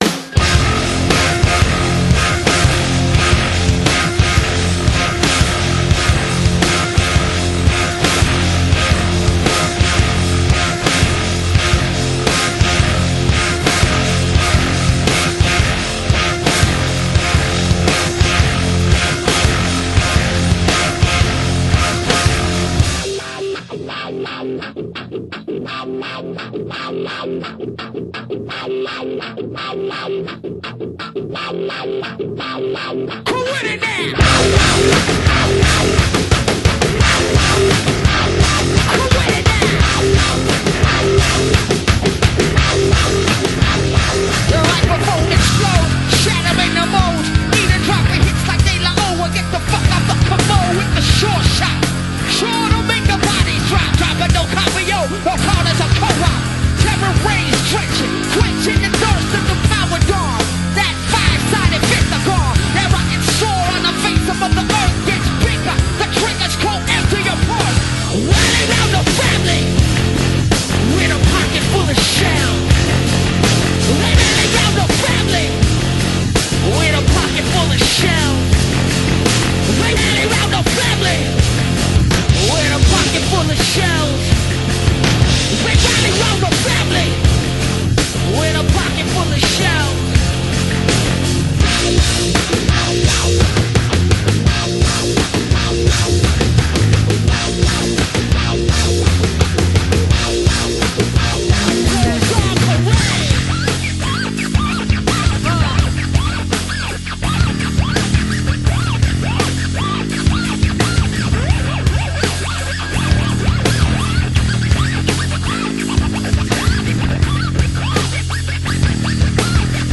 BPM160-177
Audio QualityMusic Cut